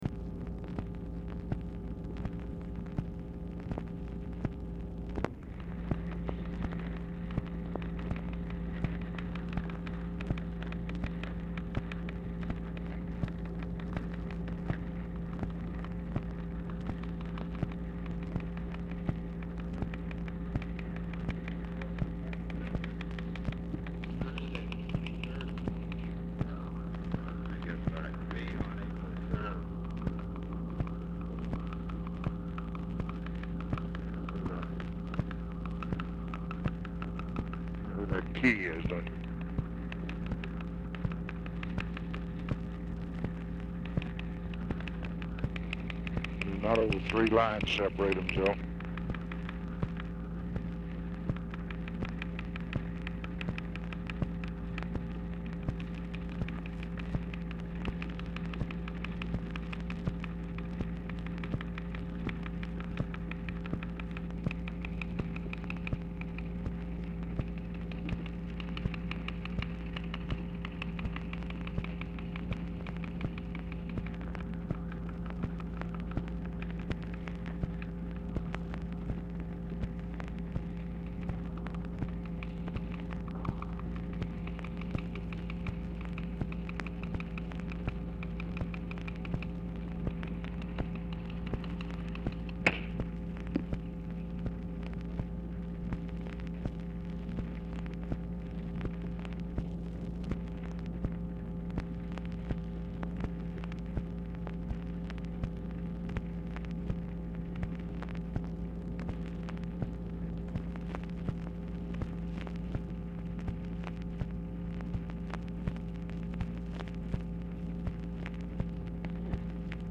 TICKER TAPE AUDIBLE; LBJ ON HOLD 2:15; OPERATOR UPDATES LBJ ON EFFORTS TO REACH MILLS
Format Dictation belt
TELEPHONE OPERATOR, OFFICE CONVERSATION
Specific Item Type Telephone conversation